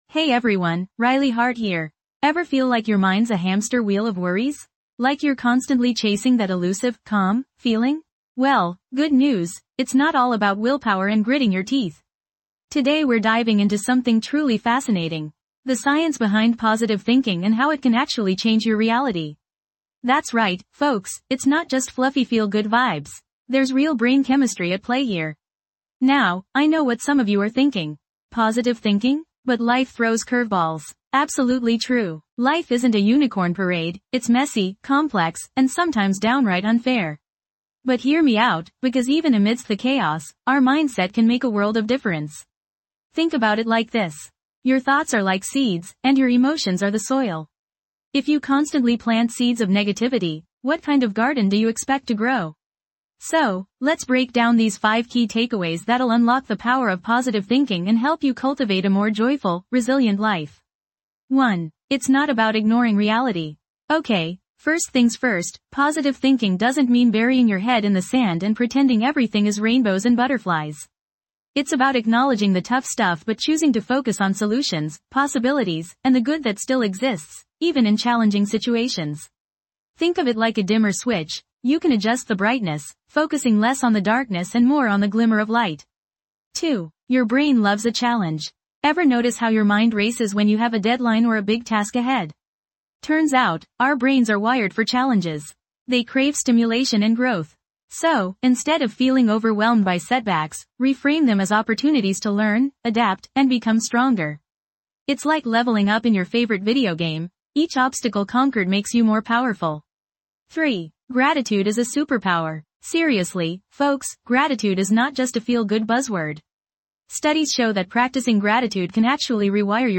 This podcast offers a soothing escape from the daily hustle, providing gentle affirmations and guided meditations designed to calm your mind, release stress, and promote a sense of tranquility. With each episode, you'll discover powerful words that can shift your perspective, quiet racing thoughts, and cultivate a deeper connection with your inner self.